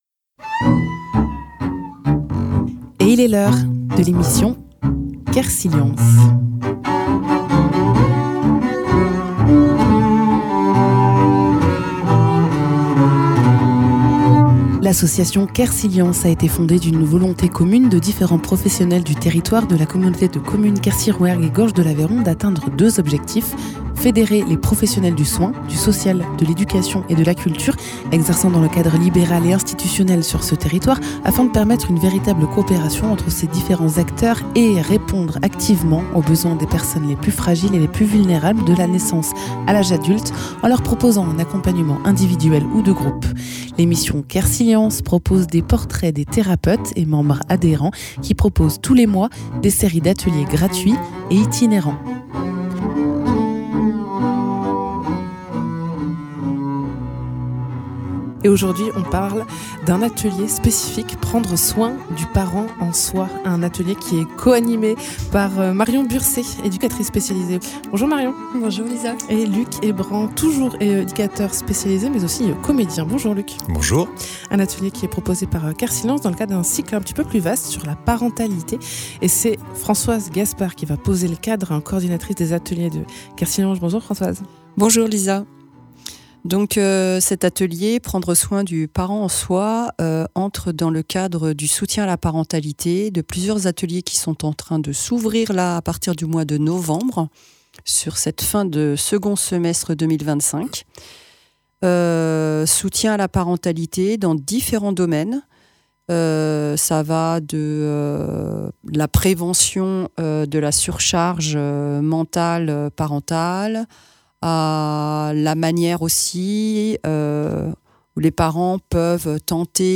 éducateur-trice spécialisé-ée